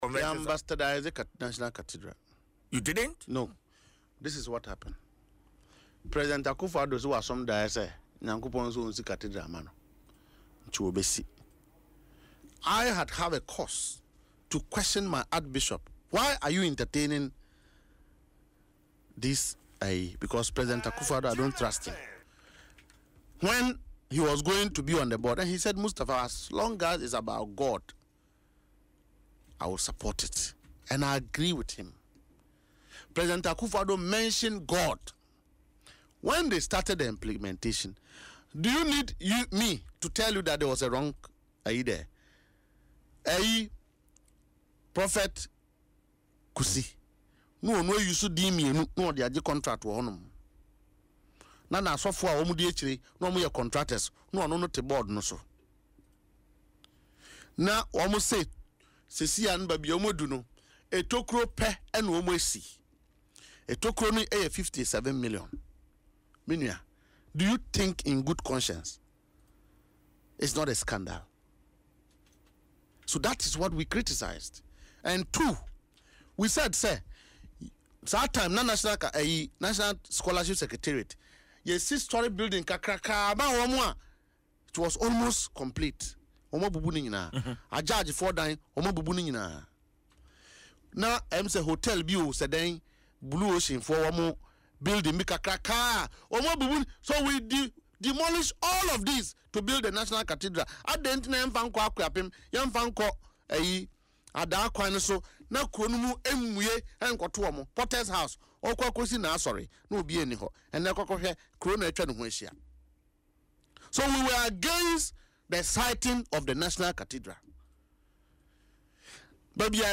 on Adom FM’s Dwaso Nsem